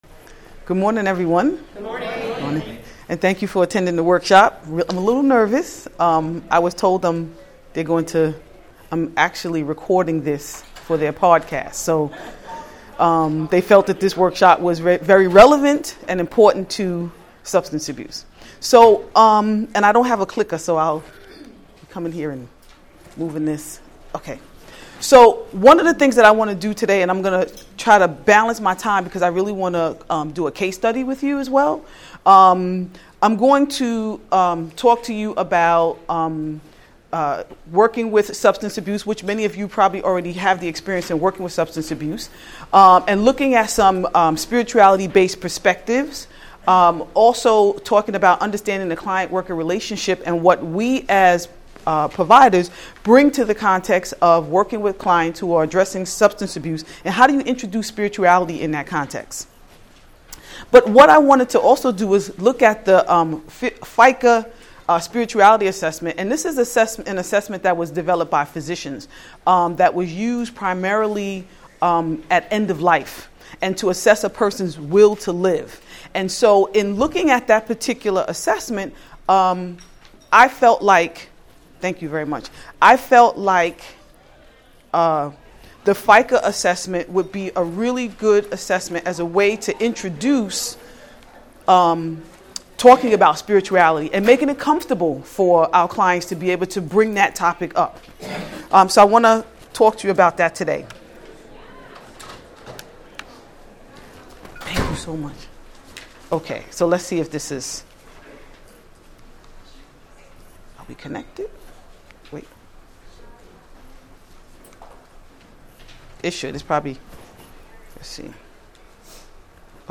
Training format: audio-based